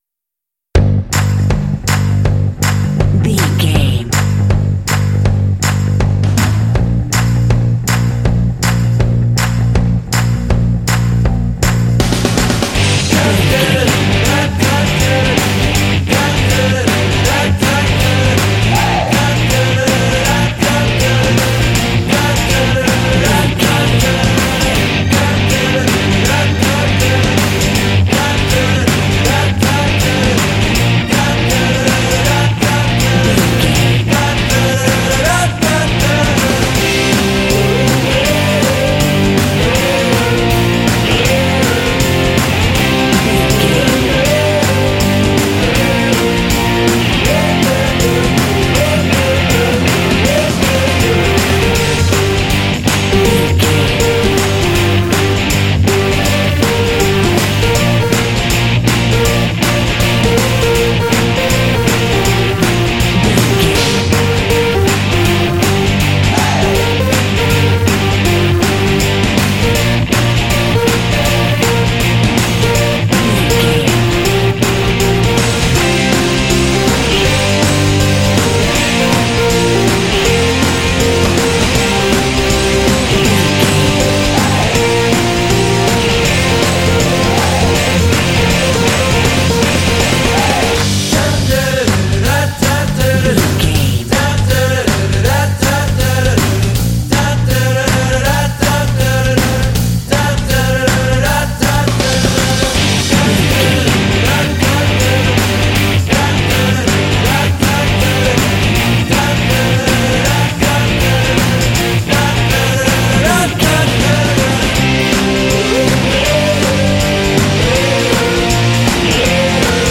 Uplifting
Aeolian/Minor
driving
powerful
energetic
groovy
positive
drum machine
electric guitar
synthesiser
vocals
bass guitar
alternative
indie
pop